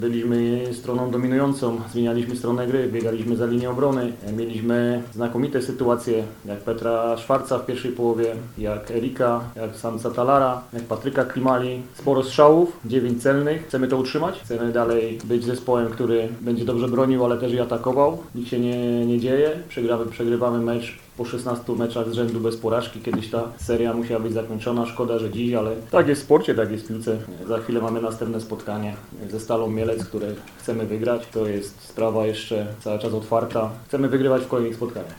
Na pomeczowej konferencji prasowej głos zabrał Jacek Magiera, trener Śląska Wrocław.